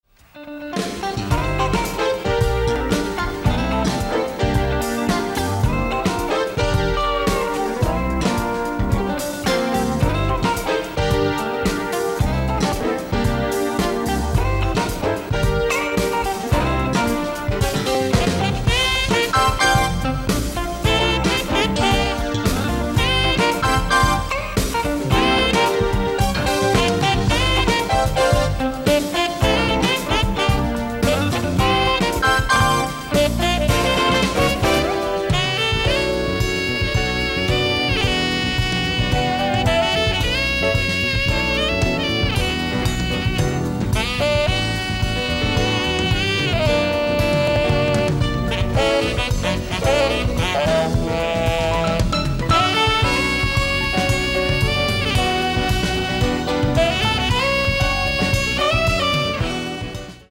ライブ・アット・ヴォス・キノ、ヴォス、ノルウェー 03/25/1988
※試聴用に実際より音質を落としています。